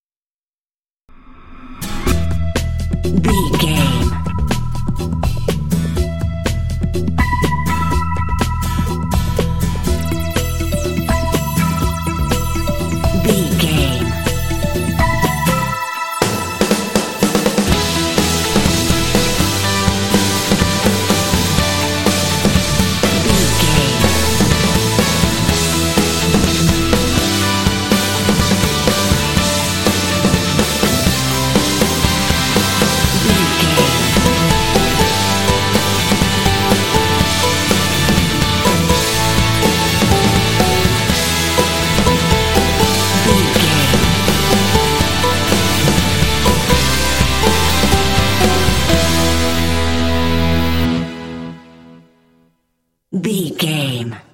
Uplifting
Ionian/Major
powerful
energetic
heavy
funky
synthesiser
drums
electric guitar
bass guitar
80s
rock
heavy metal
classic rock